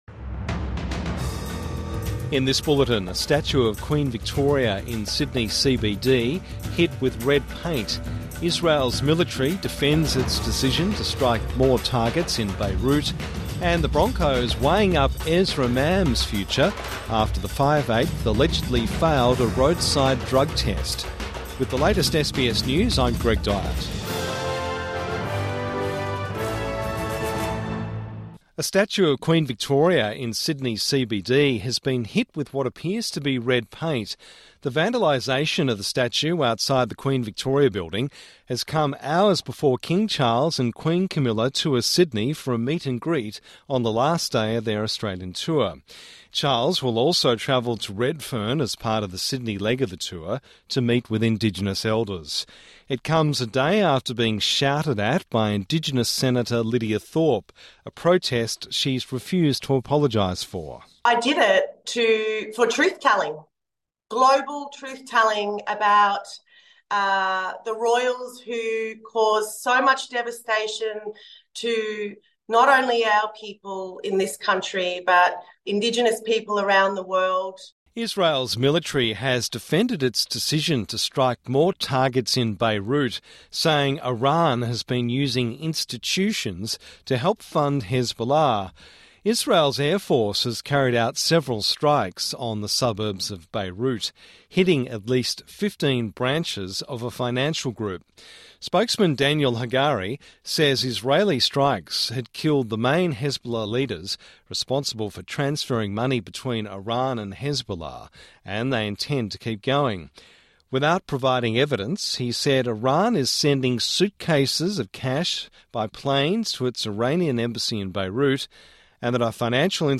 Midday News Bulletin 22 October 2024